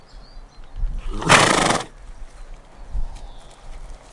牧场之声" 逆向马的脚步声在砾石上02
描述：我扭转了砾石上一些蹄台阶的波形。